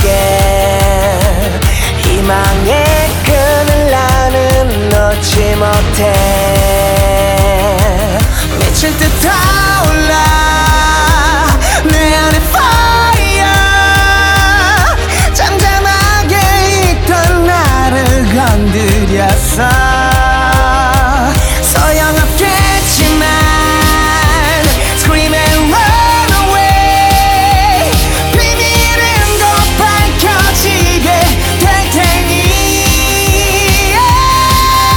Жанр: Поп / K-pop / Музыка из фильмов / Саундтреки